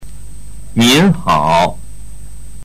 ニン　ハオ